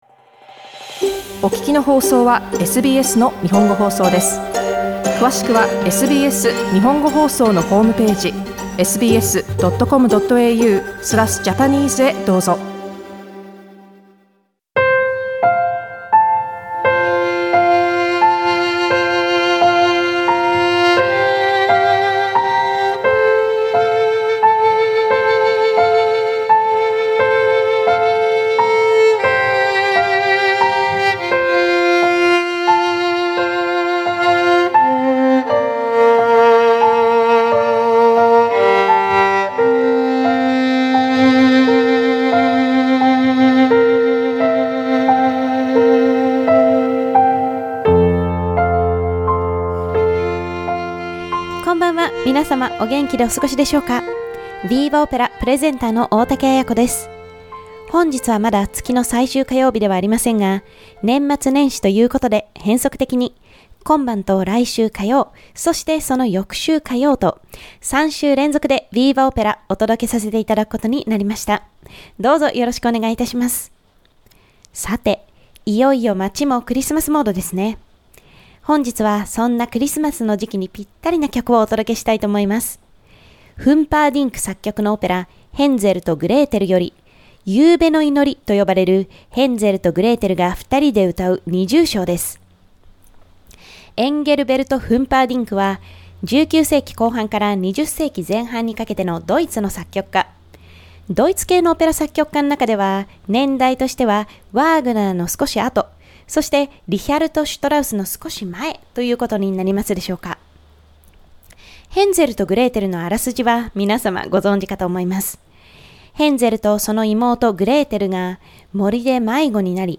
Sydney-based Japanese soprano singer landed in a "fighter cook" role in a new contemporary Australian opera VIVA! Opera is a monthly content broadcast on the last Tuesday of each month.